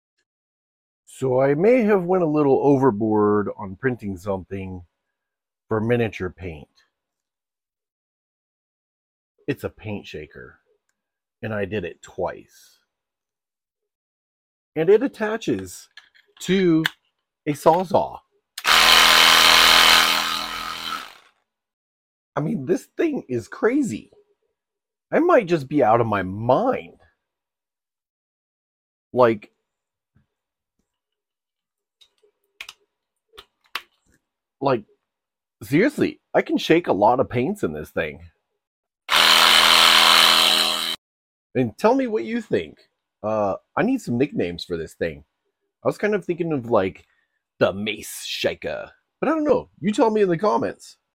Over kill paint shaker for sound effects free download